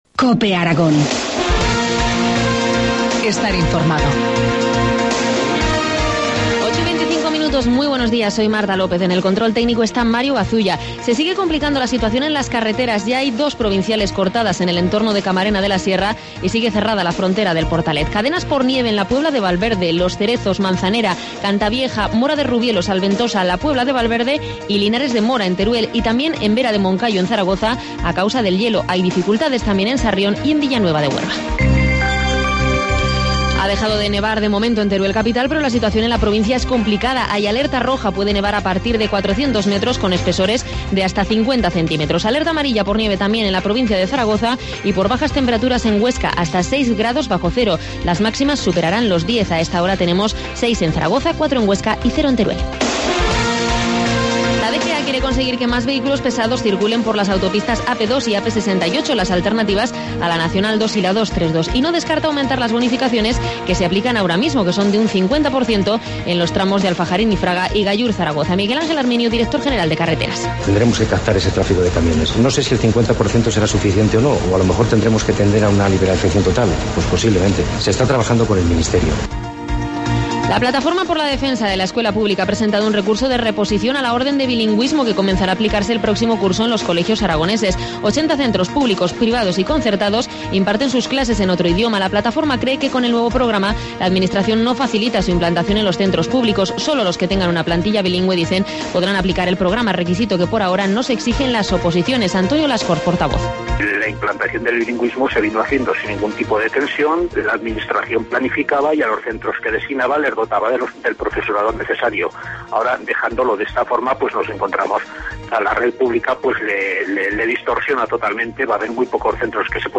Informativo matinal, jueves 28 de febrero, 8.25 horas